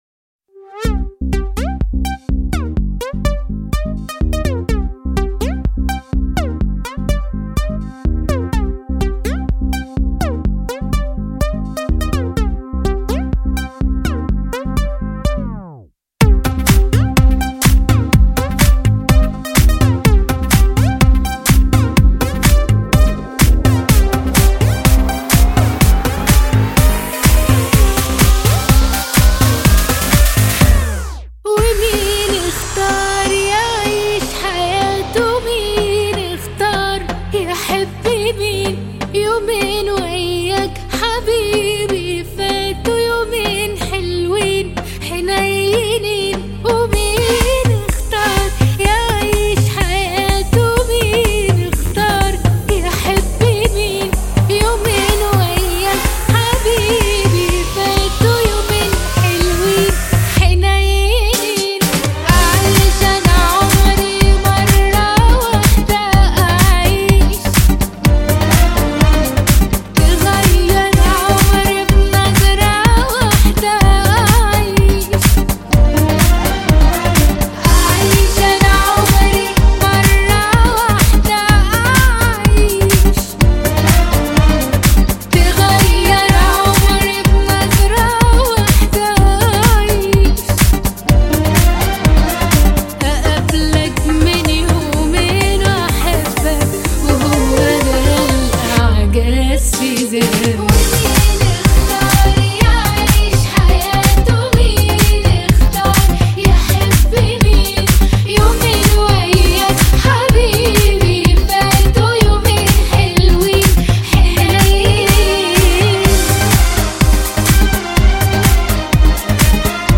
اهنگ عربی
شاد عربی